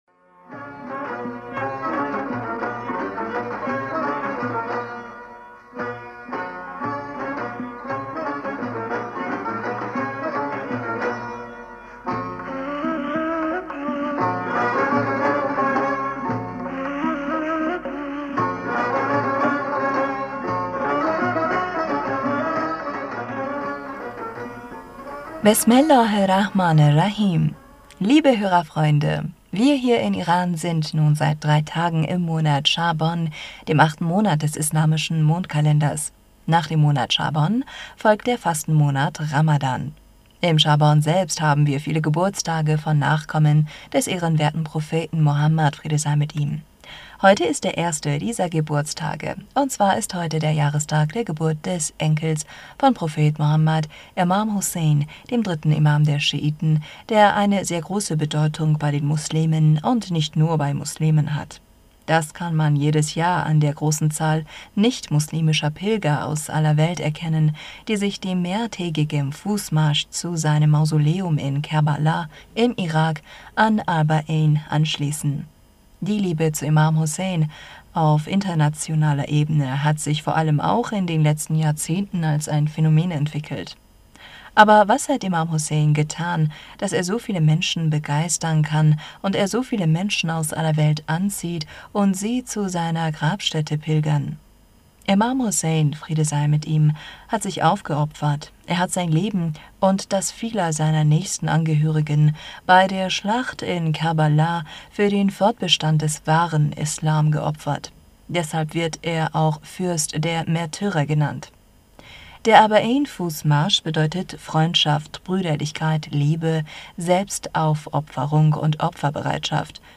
Hörerpostsendung am 06. März 2022 Bismillaher rahmaner rahim - Liebe Hörerfreunde wir hier in Iran sind nun seit 3 Tagen im Monat Shaban dem 8 Monat des i...